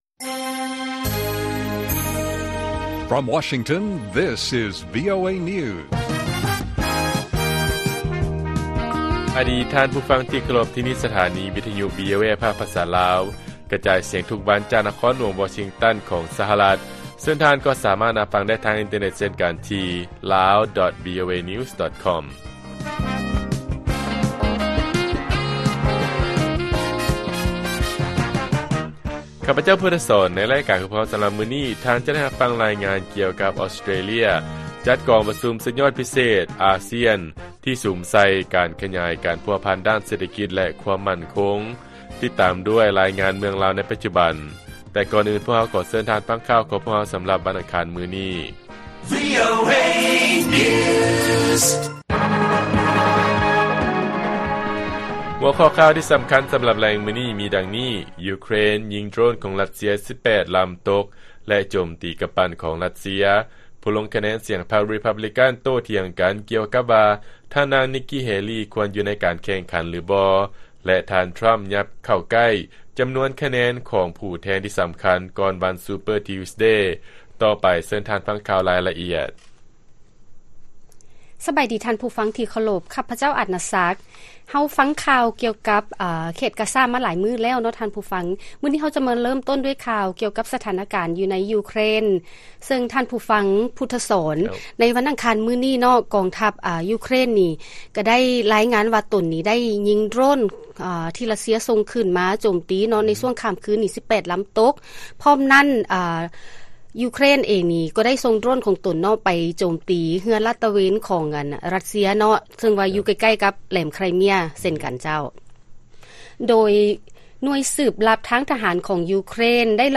ລາຍການກະຈາຍສຽງຂອງວີໂອເອ ລາວ: ອອສເຕຣເລຍ ເປັນເຈົ້າພາບຈັດກອງປະຊຸມພິເສດສຸດຍອດ ລະດັບພາກພື້ນຂອງອາຊຽນ